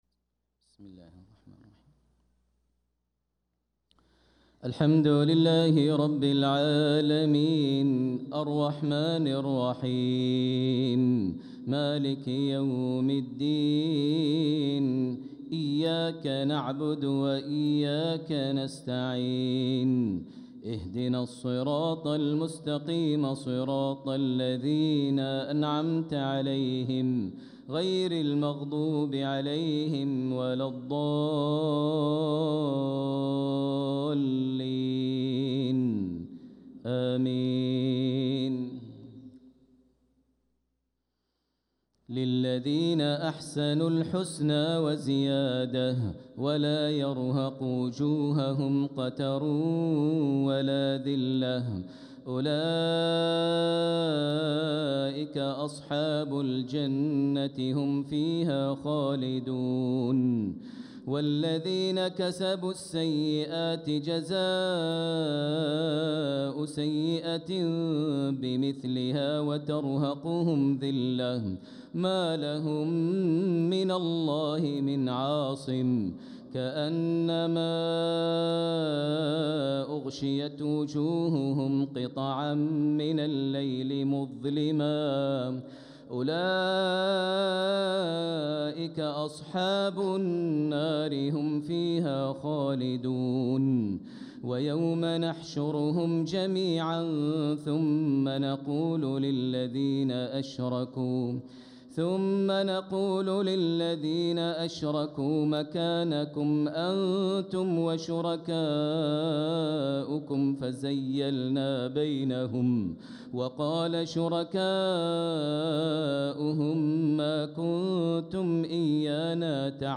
صلاة العشاء للقارئ ماهر المعيقلي 27 صفر 1446 هـ
تِلَاوَات الْحَرَمَيْن .